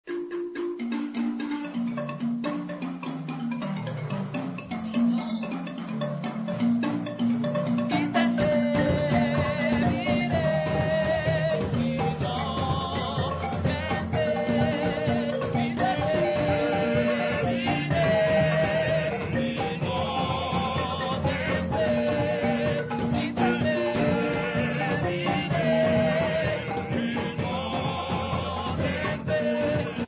Folk music
Sacred music
Field recordings
Africa South Africa Alice sa
sound recording-musical
University of Fort Hare music students